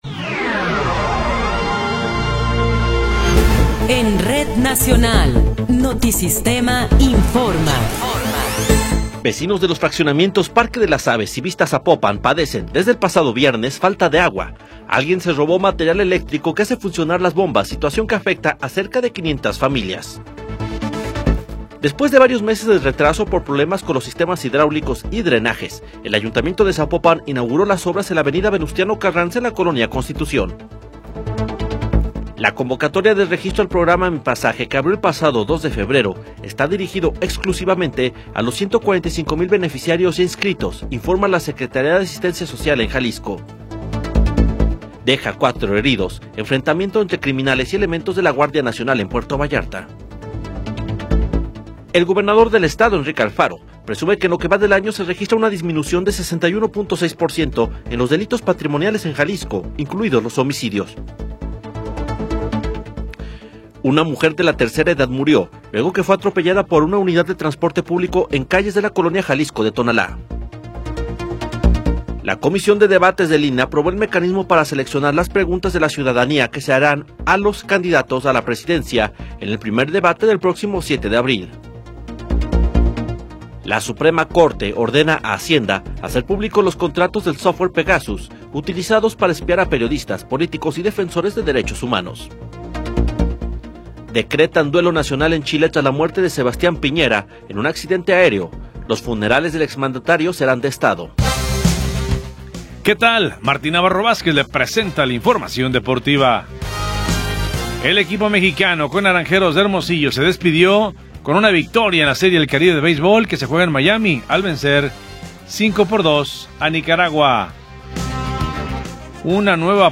Noticiero 21 hrs. – 6 de Febrero de 2024